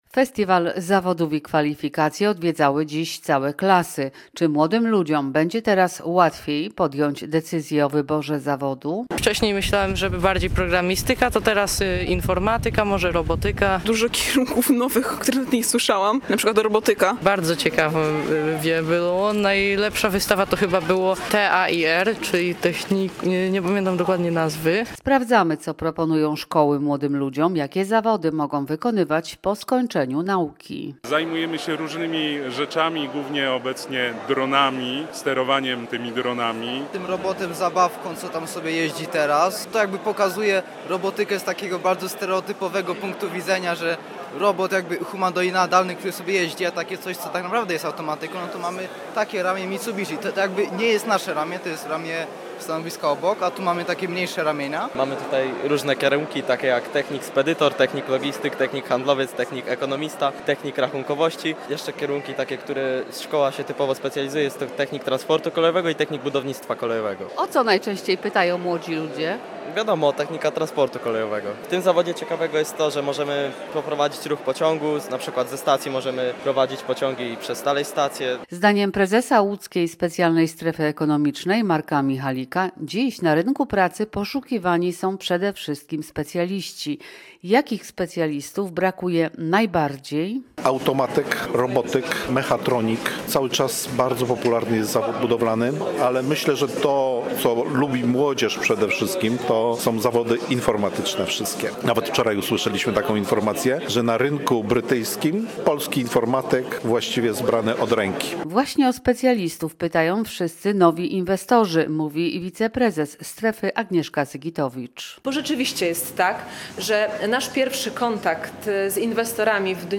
Posłuchaj relacji i dowiedz się więcej: Nazwa Plik Autor Festiwal Zawodów i Kwalifikacji w ŁSSE.